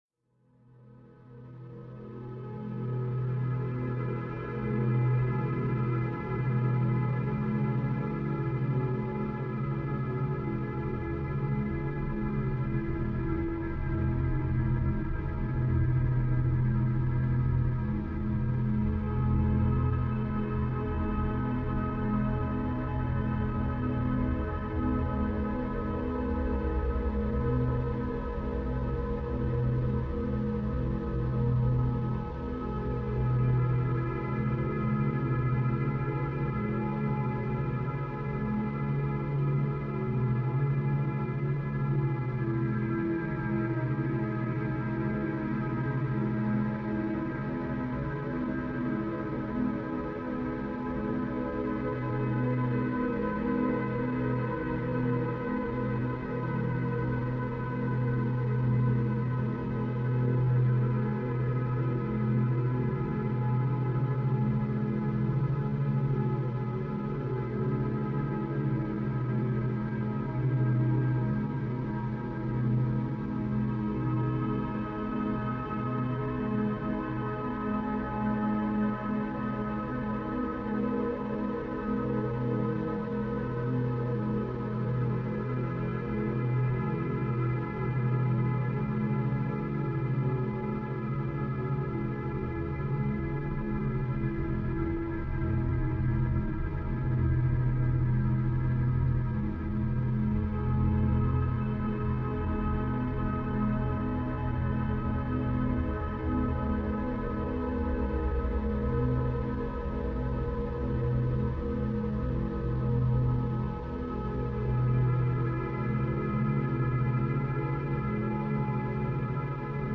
ژانر: چاکرا